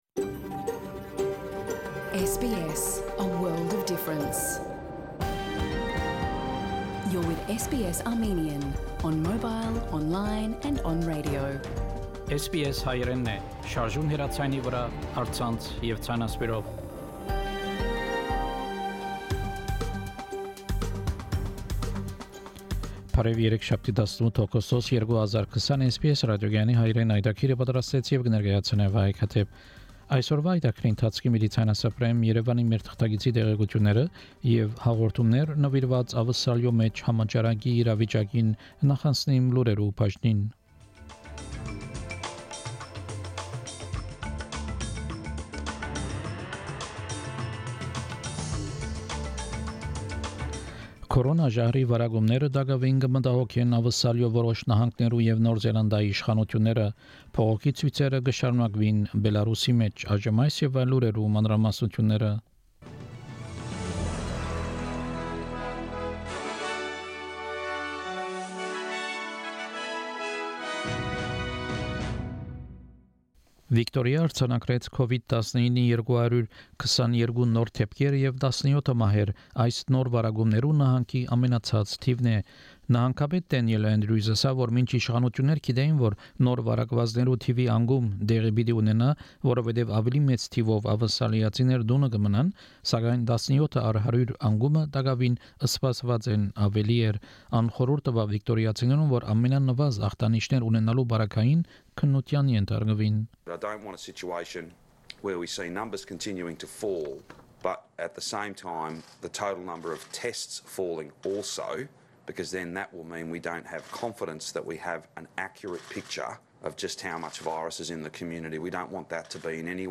SBS Armenian news bulletin – 18 August 2020
SBS Armenian news bulletin from 18 August 2020 program.